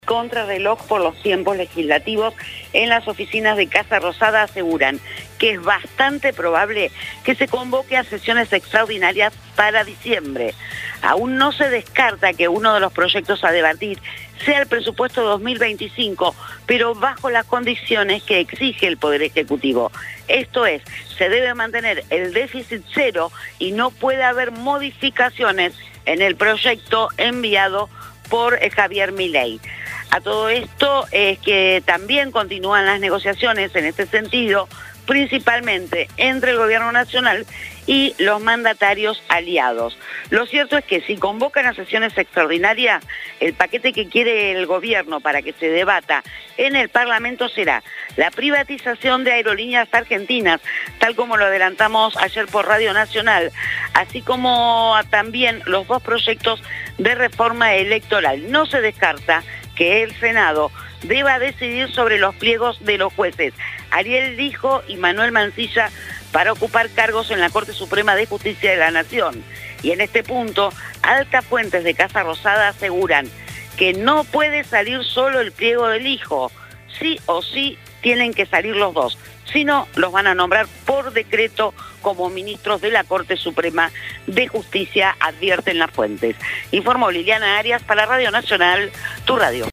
LA-Congreso-Boletin.mp3